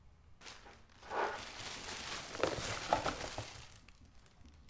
将小件物品倒入垃圾袋中
描述：把小块的东西倒进垃圾袋。
Tag: 垃圾袋